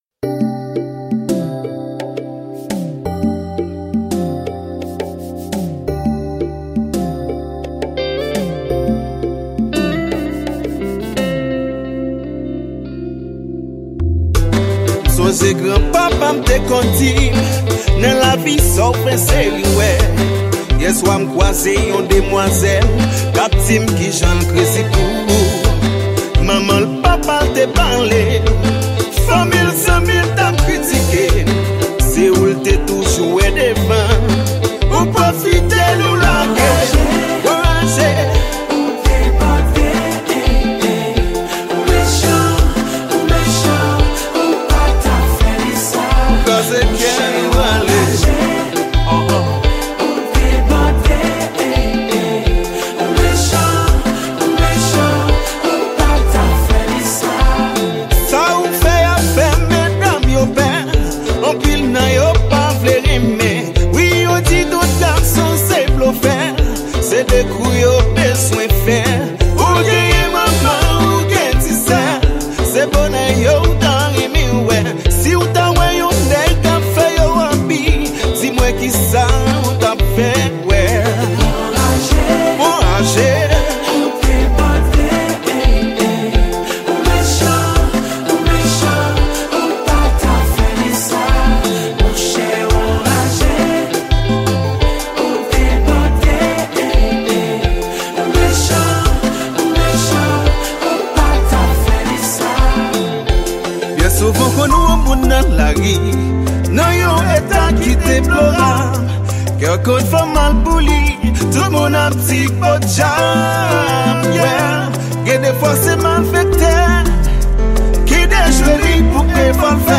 Genre: Compas.